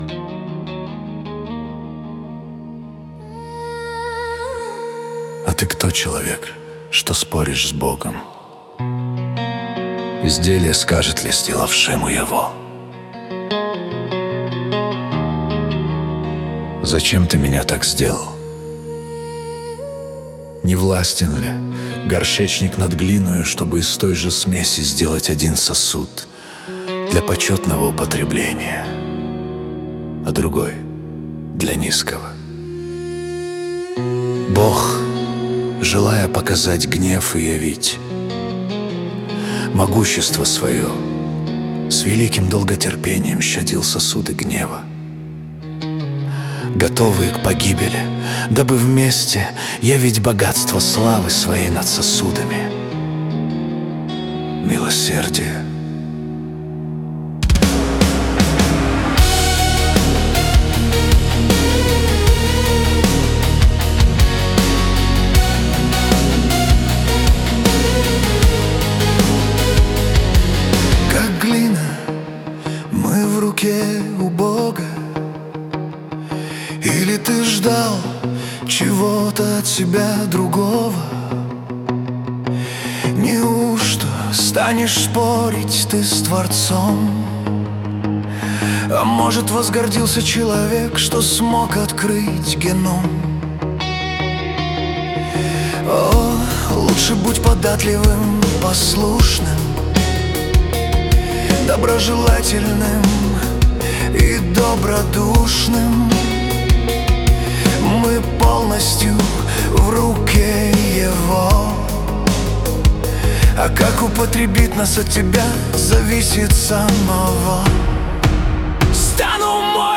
песня ai
131 просмотр 579 прослушиваний 56 скачиваний BPM: 75